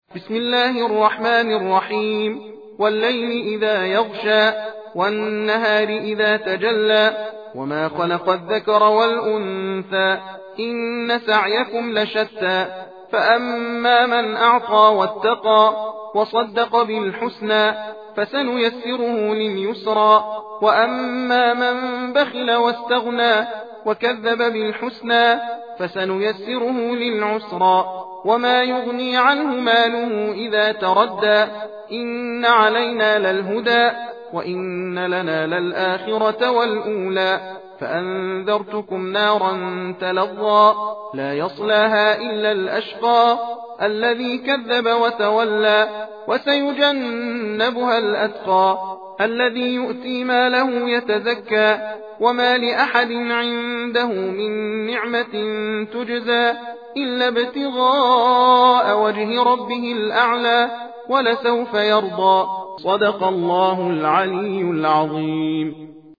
تحدیر (تندخوانی) سوره لیل + متن و ترجمه